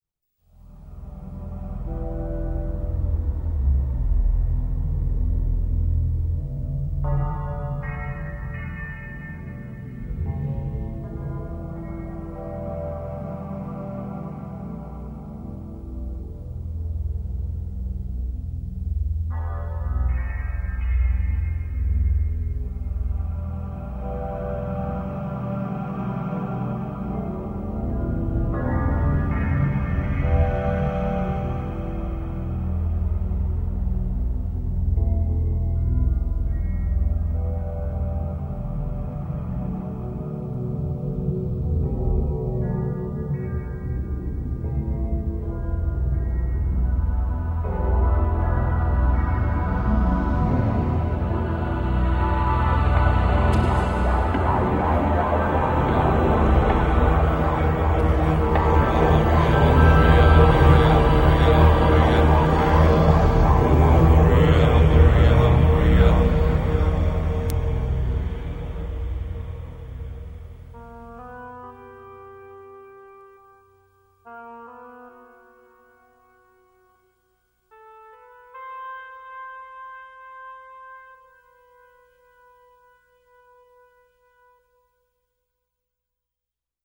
类别:电影原声
此曲同样用颂经和电子音乐表达无法宣泄的忿恨。